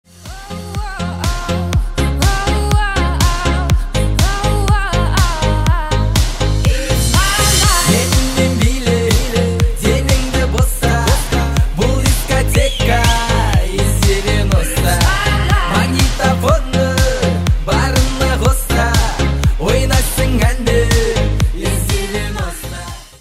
• Качество: 192, Stereo
поп
веселые